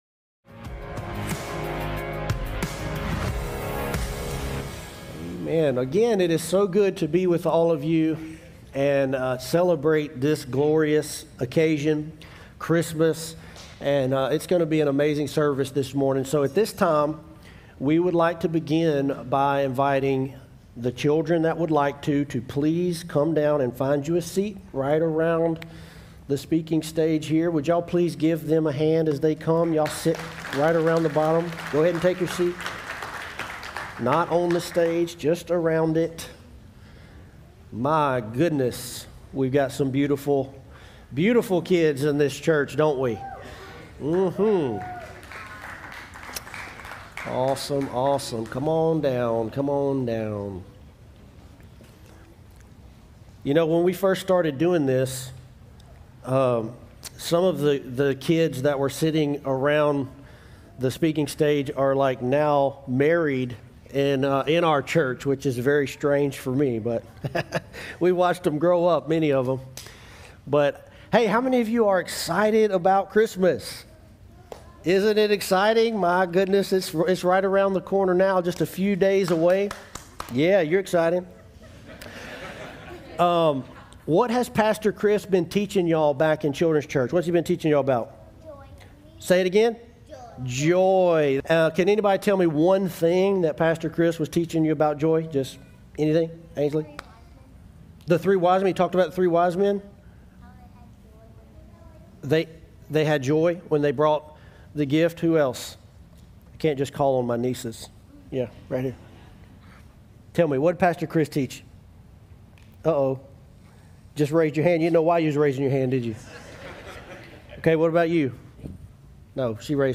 Joy Sermon Series - One Life Christmas 2024